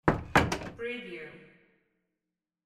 Door close sound effect .wav #19
Description: Room door closes
Properties: 48.000 kHz 24-bit Stereo
Keywords: door, close, closing, pull, pulling, push, pushing, shut, shutting, house, apartment, office, room
door-19-close-preview-1.mp3